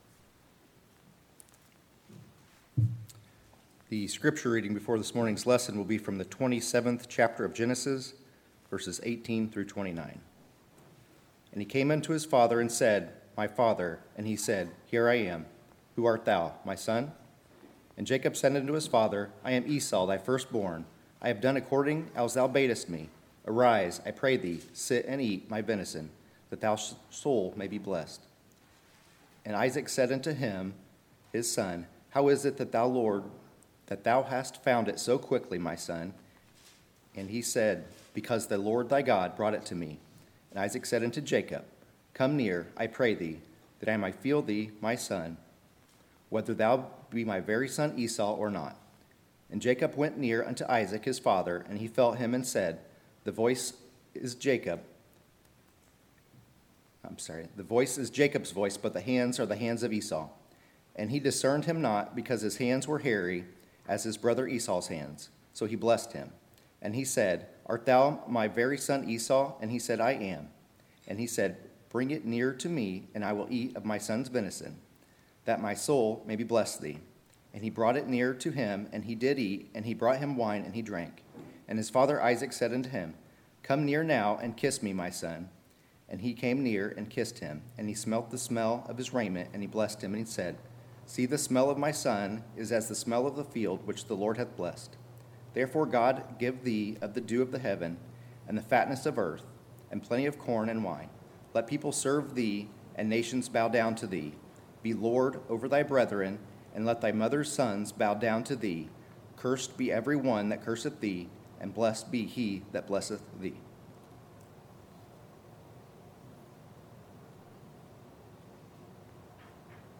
Sermons, July 5, 2020